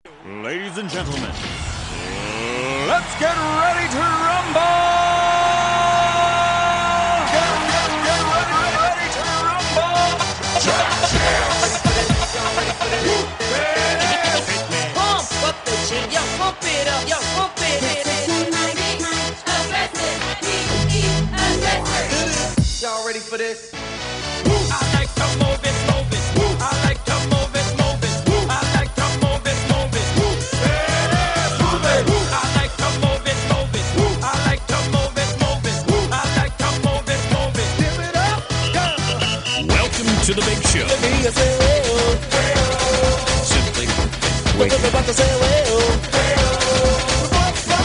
O Melhor MEGAMIX dos últimos anos!!
Com o melhor do Flashback!!
megamix.ra